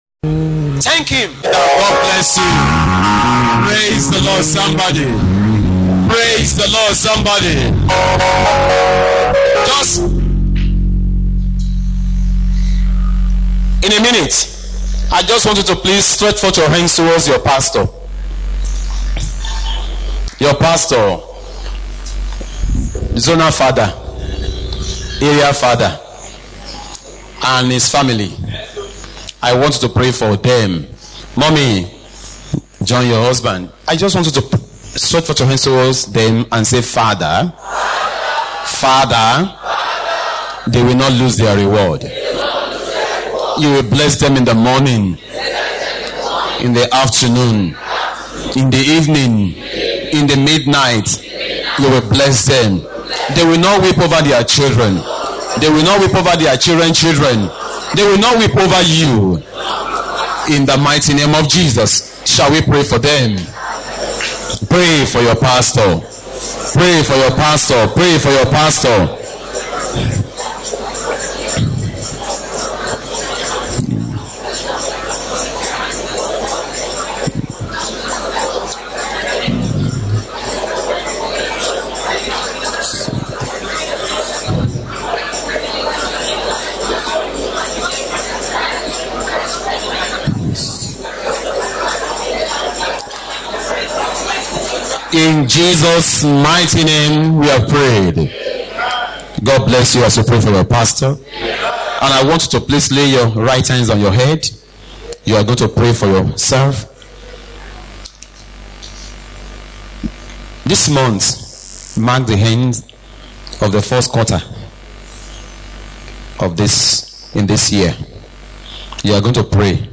Sunday service sermon